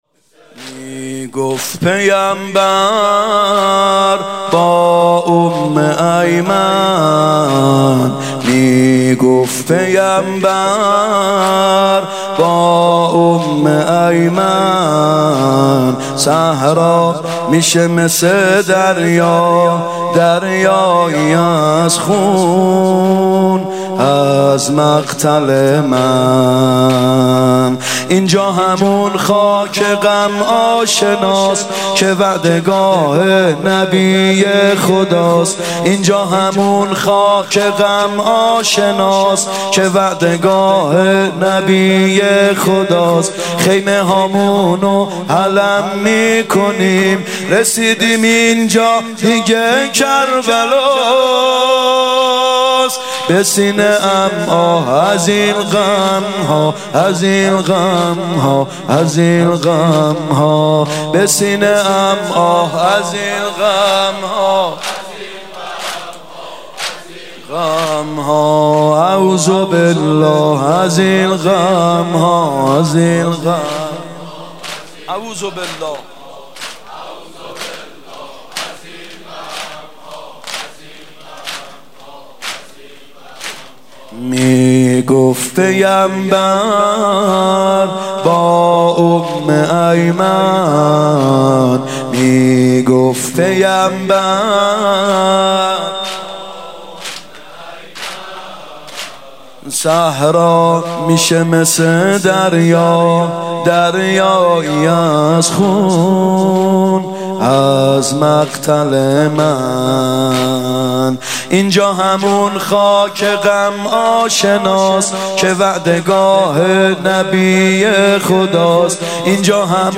محرم 96(هیات یا مهدی عج)